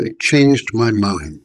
Text-to-Speech
Add metavoice samples